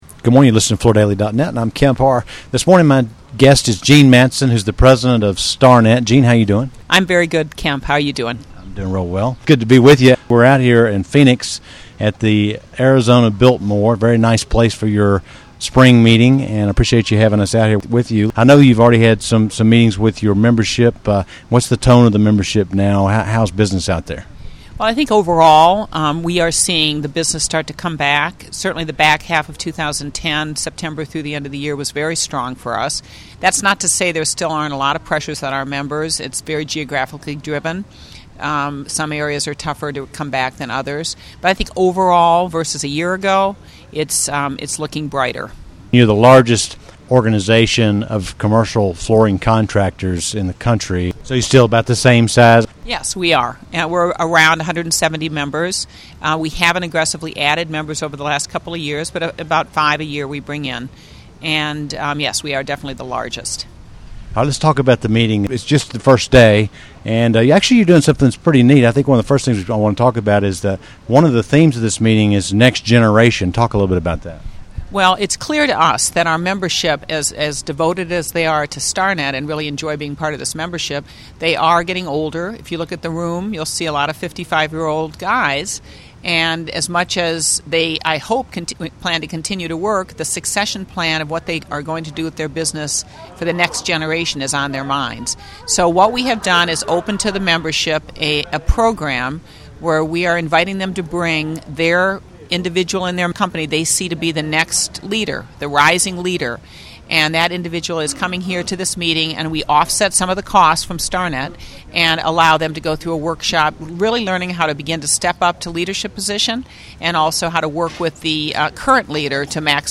Listen to the interview to hear how the largest co-op of commercial flooring contractors is doing as the economy starts to show signs of life in the commercial flooring sector. Also hear more details about Starnet's annual design contest.